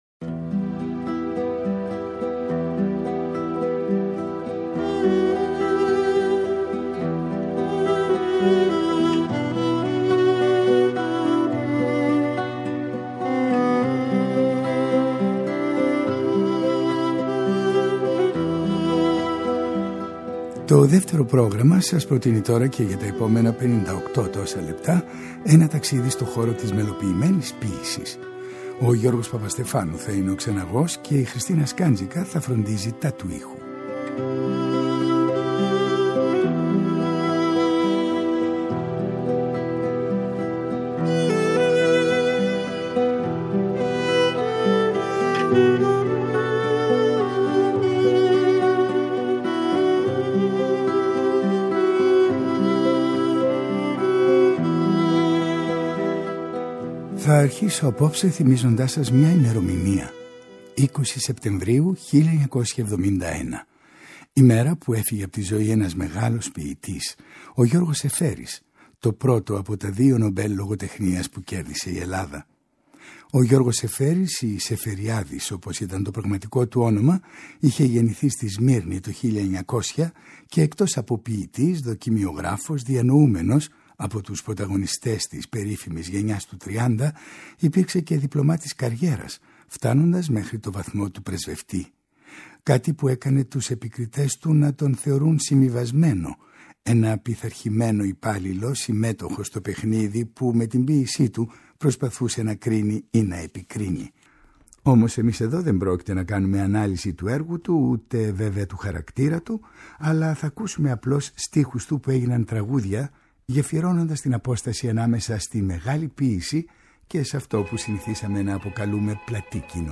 ένα ταξίδι στο χώρο της μελοποιημένης του ποίησης .